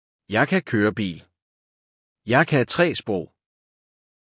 • Ich kann Auto fahren - Jeg kan køre bil - Jai känn kööa biil
• Ich kann 3 Sprachen - Jeg kan tre sprog - Jai känn tree sproog